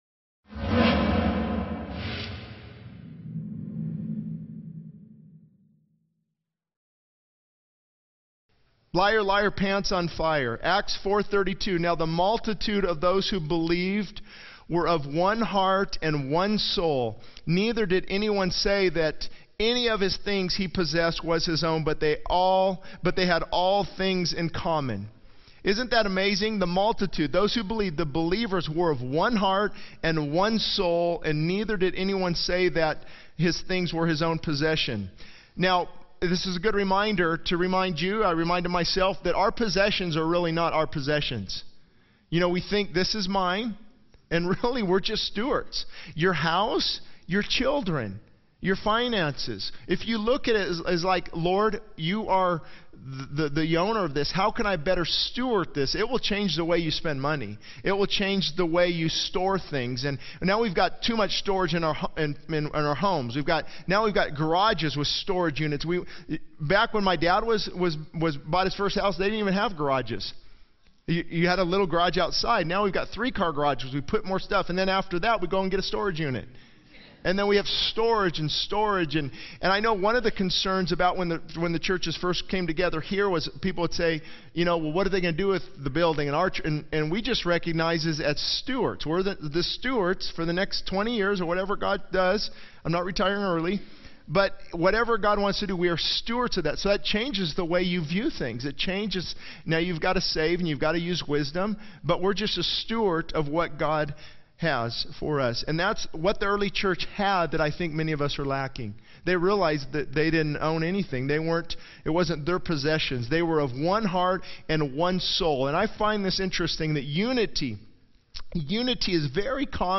This sermon emphasizes the importance of honesty and repentance, highlighting the consequences of habitual lying and the need for genuine confession and transformation. It addresses the impact of lying on relationships, spiritual vitality, and eternal consequences, urging individuals to seek God's forgiveness and cleansing in areas of deceit.